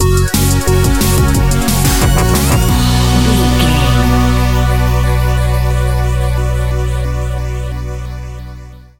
Aeolian/Minor
aggressive
dark
driving
energetic
drum machine
synthesiser
sub bass
synth leads